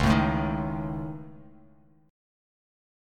C#sus2#5 chord